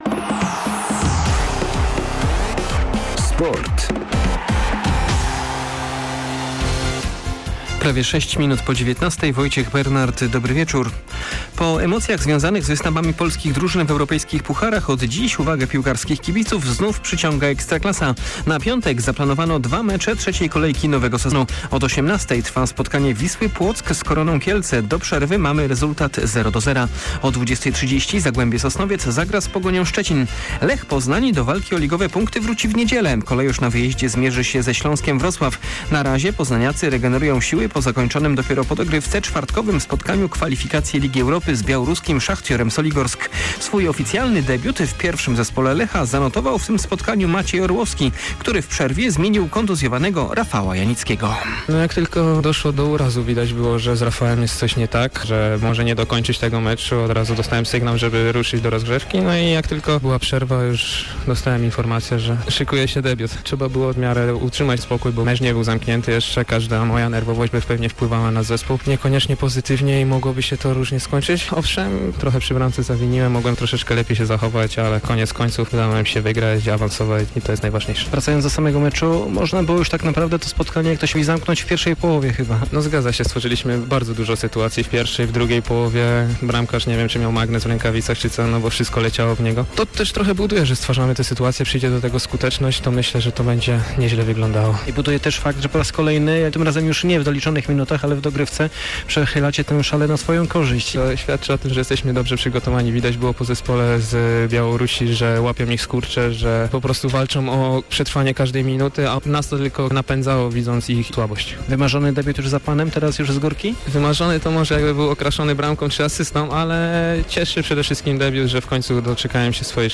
03.08 serwis sportowy godz. 19:05